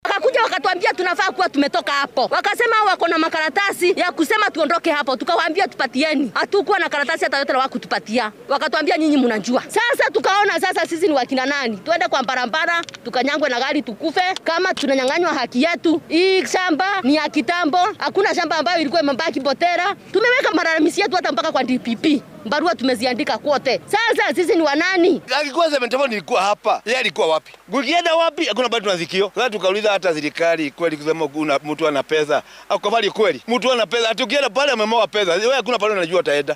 Jogoo-Road.mp3